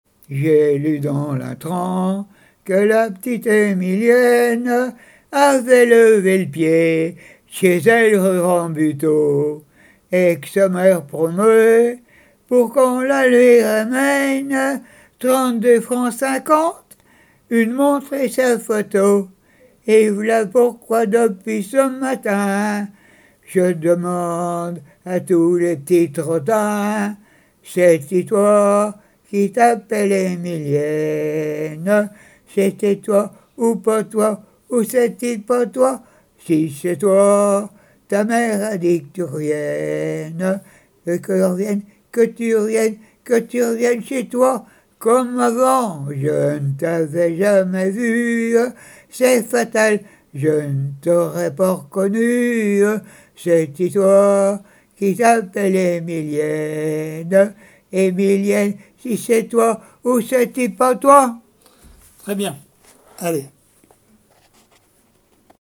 Genre strophique
chansons traditionnelles et d'école
Pièce musicale inédite